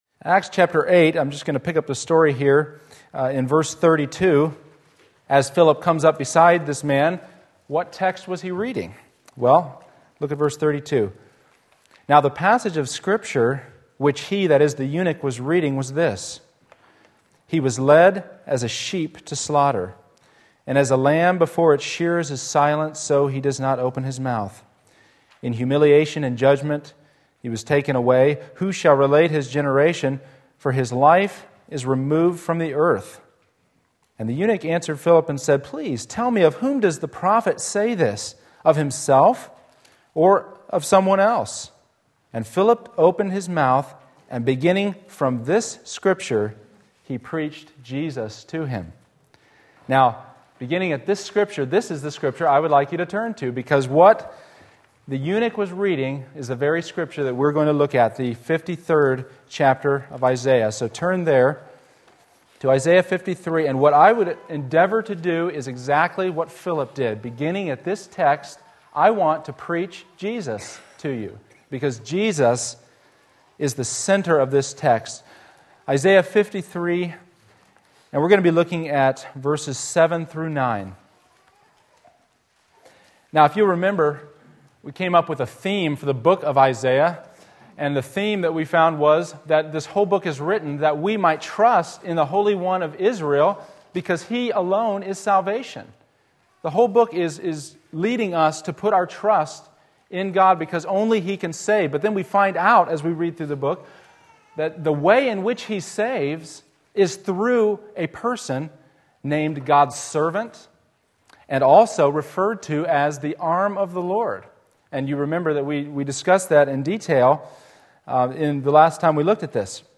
Sermon Link
The Righteous Suffering of the Arm of the Lord Isaiah 53:7-9 Sunday Morning Service